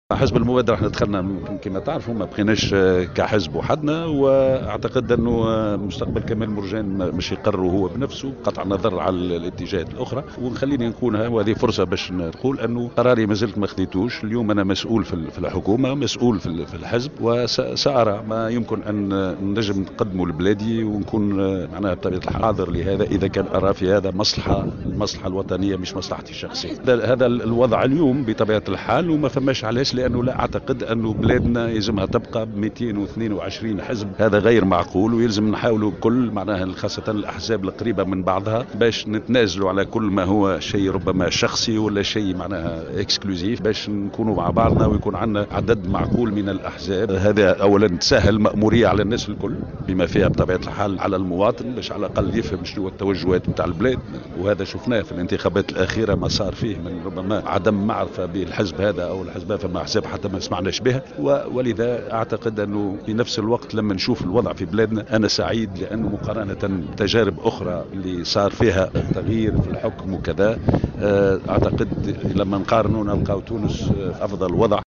أكد وزير الوظيفة العمومية وتحديث الإدارة والسياسات العمومية كمال مرجان، في تصريح للجوهرة أف أم، اليوم الأحد، أنه سيقوم، في الوقت المناسب، بتحديد مستقبله السياسي، إثر انتهاء عهدته في الحكومة الحالية.